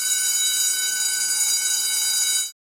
School Bell.wav